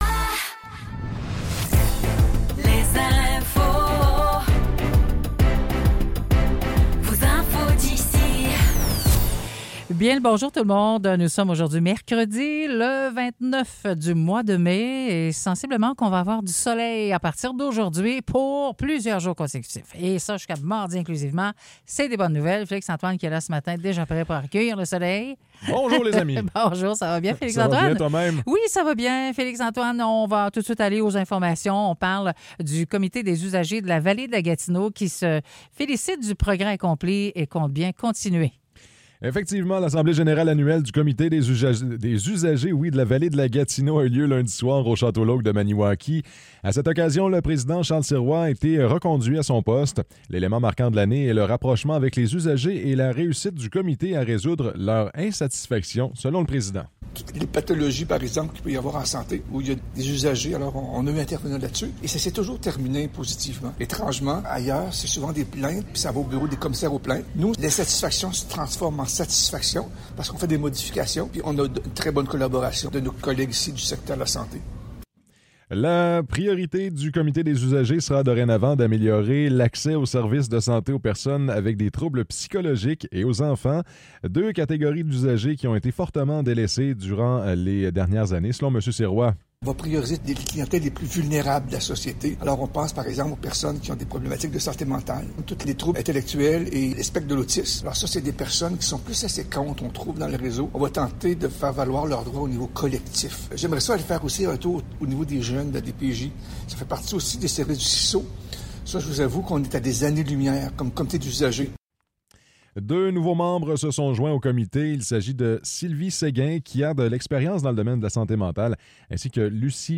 Nouvelles locales - 29 mai 2024 - 9 h